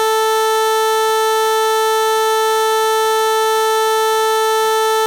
Beim folgenden Beispiel handelt es sich um die besonderen Wellen des Round Saw, alle gespielt auf dem Kammerton A4.
Round Saw